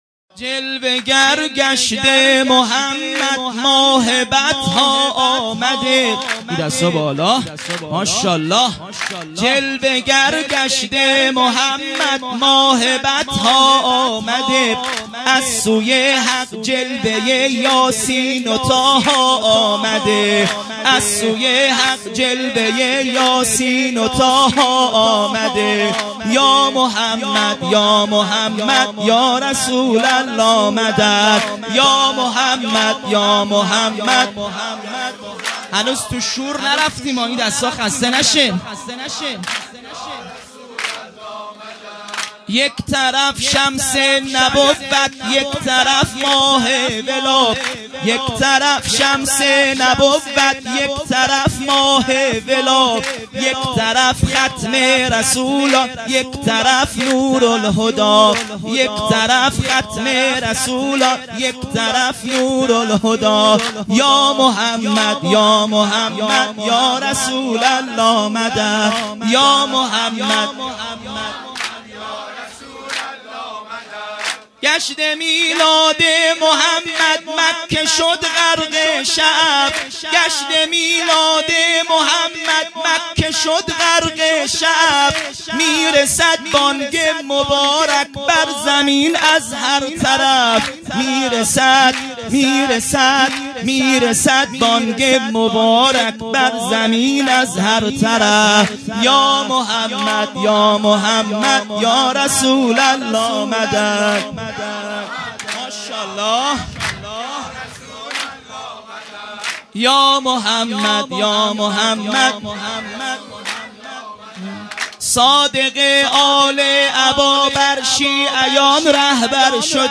هیئت مکتب الزهرا(س)دارالعباده یزد - سرود ۱ |جلوه گر گشته محمد مداح